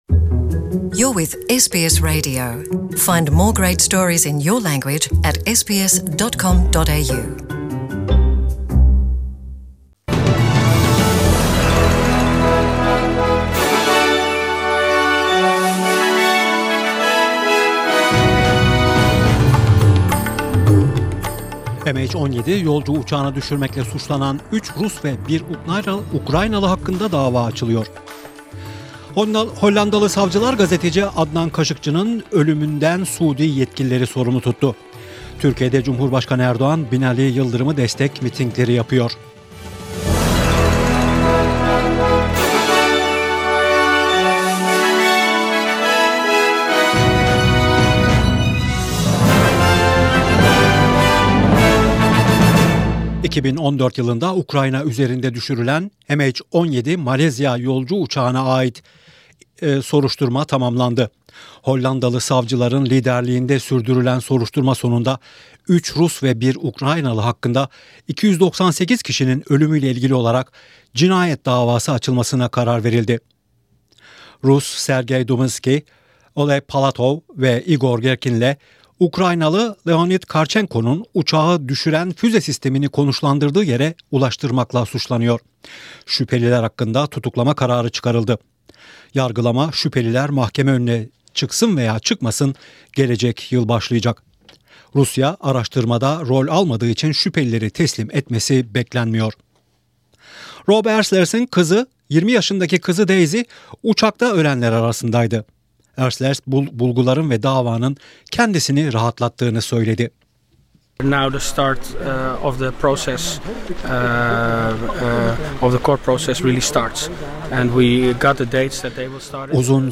SBS Radyo Türkçe'den Avustralya, Türkiye ve dünyadan haberler. Başlıklar: *Birleşmiş Milletler, İran’ın Amerika Birleşik Devletlerine ait insansız hava aracını düşürmesi sonrasında, iki tarafa itidal çağrısında bulundu.